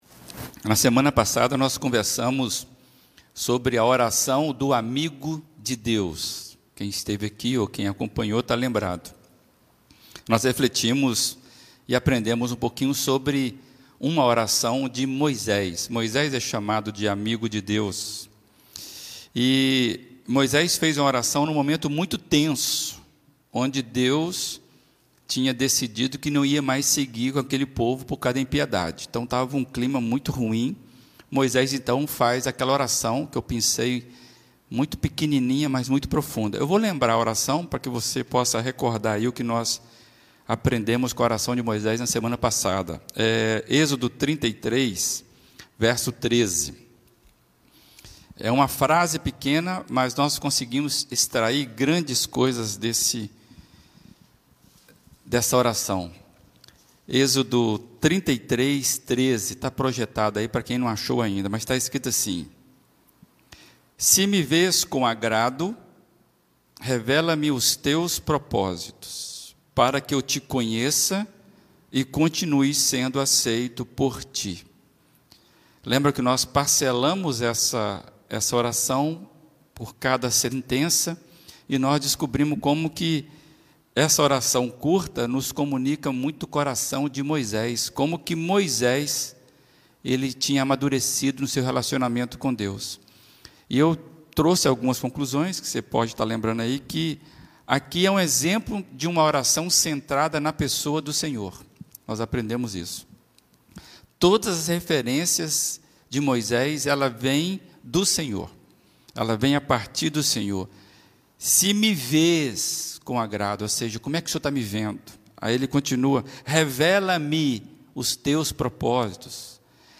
Reflexões das Quartas-feiras